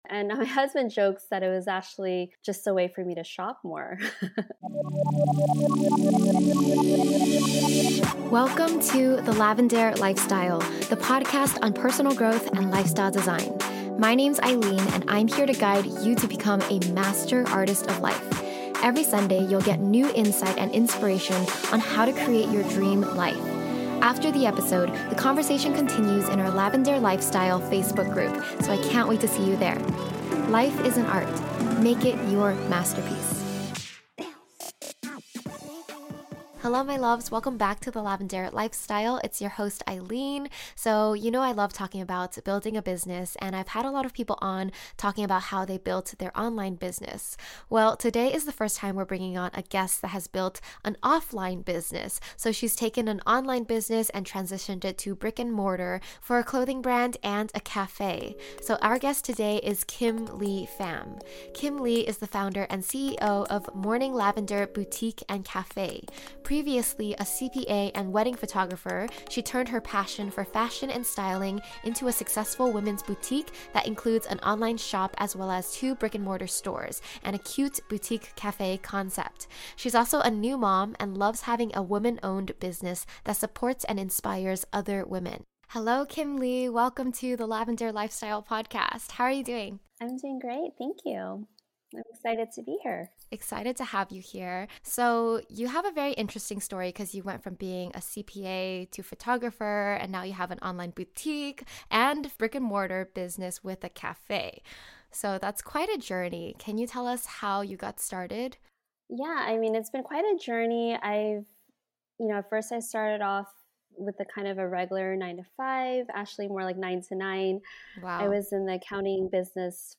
In the interview, she talks about starting a business from a personal need, how essential a brand vision is, and her strategies for building a supportive team.